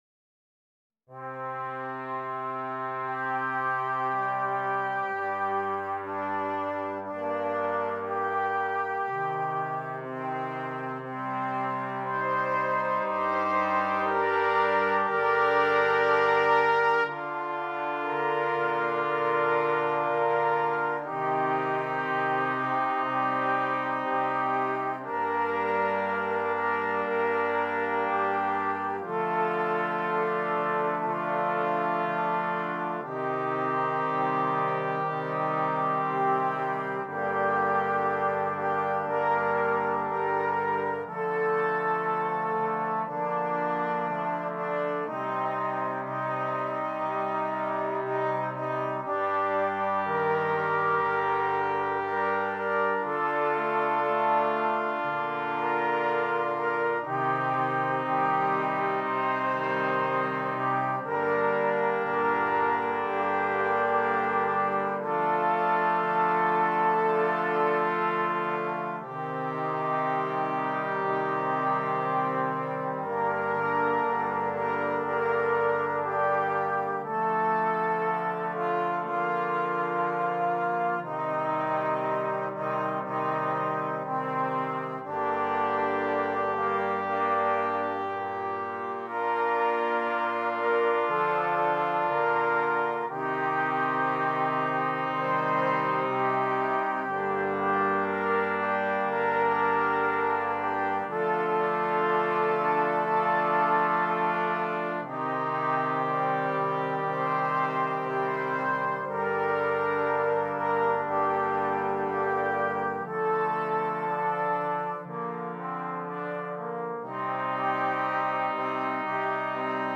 Brass Choir (3.0.4.0.0)